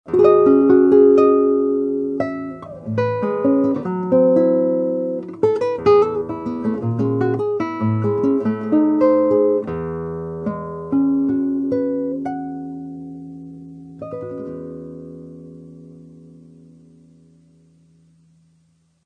A seguir são disponibilizados alguns pequenos arquivos MP3 demonstrando a sonoridade original e a sonoridade após a equalização.
Violão Takamine
Música (com eq.)